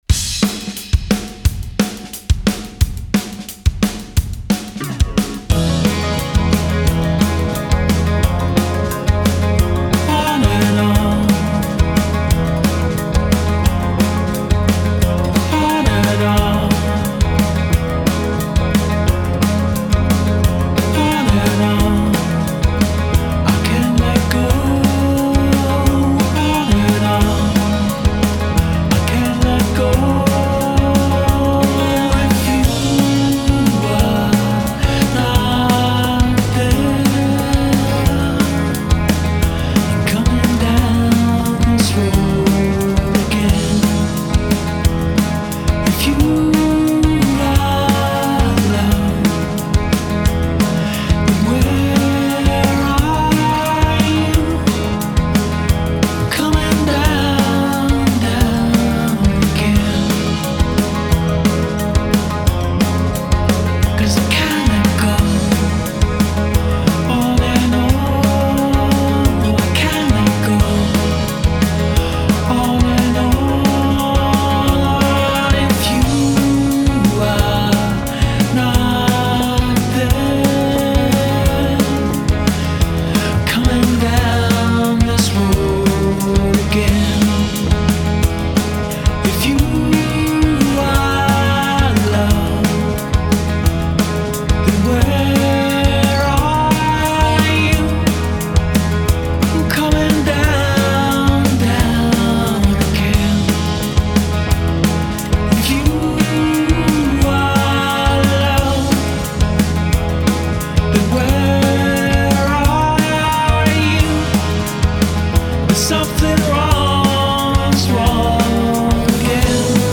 genre: progressive rock,rock